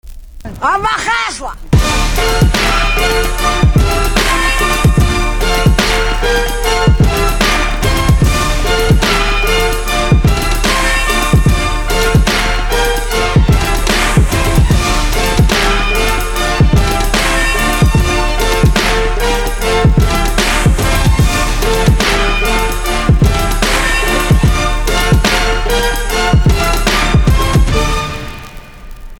Звуки для доната